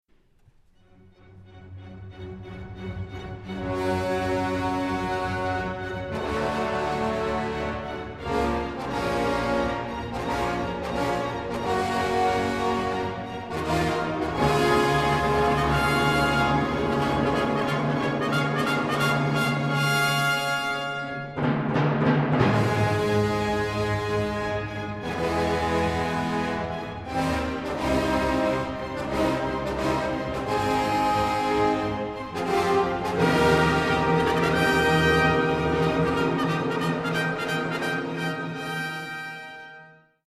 World premiere performance & recording（Adagio 2）
(TOKYO METROPOLITAN ART SPEACE,Live)
STEREO（DIGITAL）